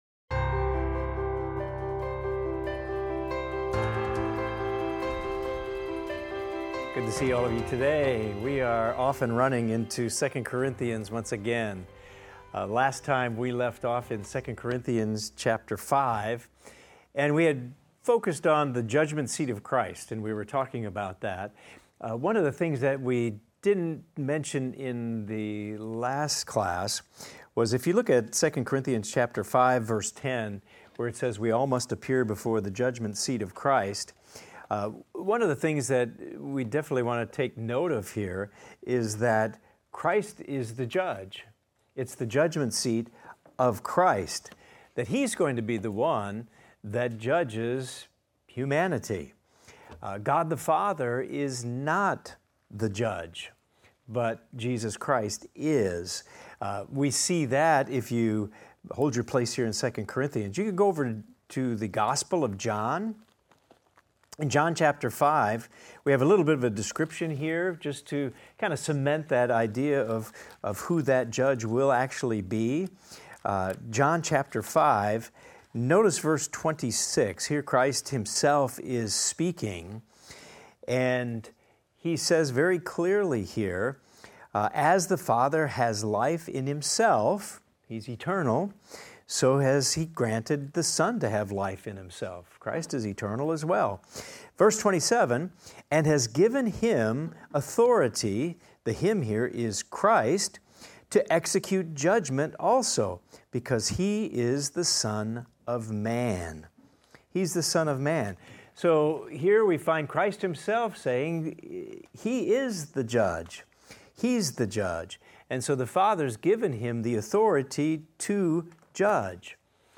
In this class we will discuss 2 Corinthians 5:12 thru 2 Corinthians 6:2 and examine the following: Paul defends his ministry, encouraging the Corinthians to be proud of his work and sincerity.